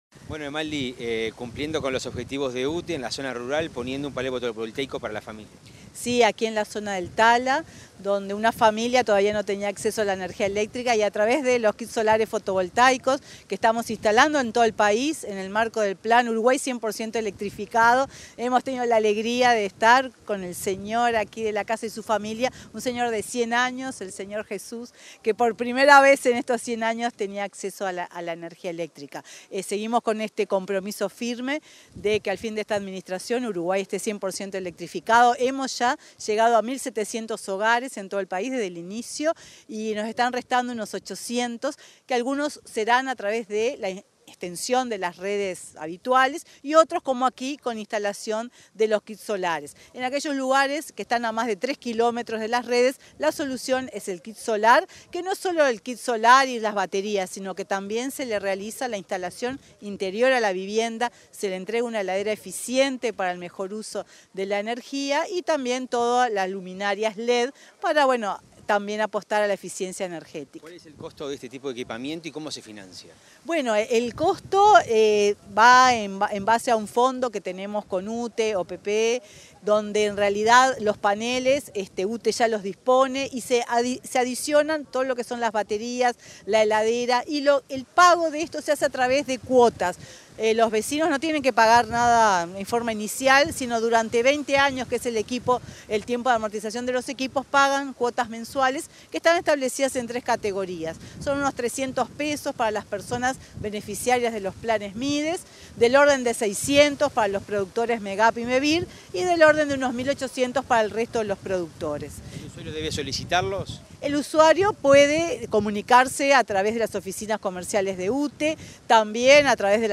Entrevista a la presidenta de UTE, Silvia Emaldi
Entrevista a la presidenta de UTE, Silvia Emaldi 19/09/2023 Compartir Facebook X Copiar enlace WhatsApp LinkedIn Tras la inauguración de los equipos fotovoltaicos en el Plan Uruguay 100% Electrificado, en Tala, departamento de Canelones, la presidenta de UTE Silvia Emaldi, dialogó con Comunicación Presidencial.